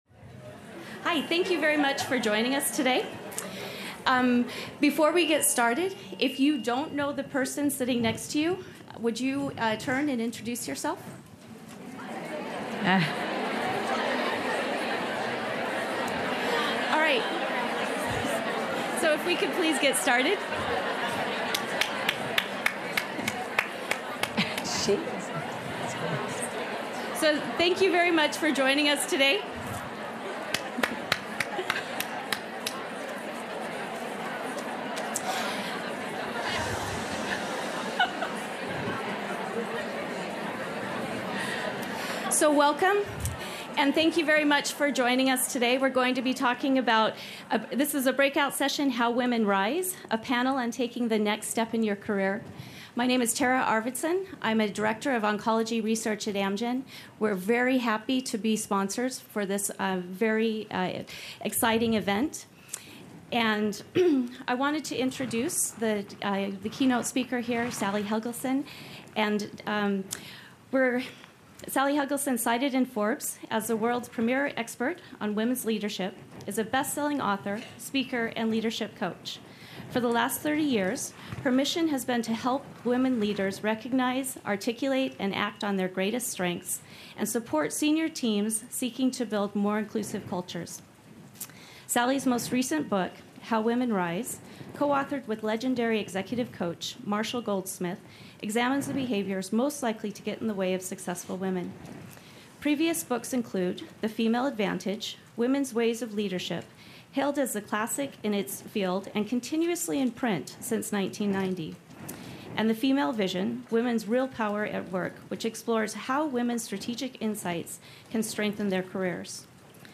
Click Play below to listen to this session from the 2019 conference.
Leadership expert Sally Helgesen will lead the conversation, identifying specific behaviors that may keep you from your goals and real solutions for realizing your full potential, no matter what stage in your career.
how-women-rise-a-panel-on-taking-the-next-step-in-your-career.mp3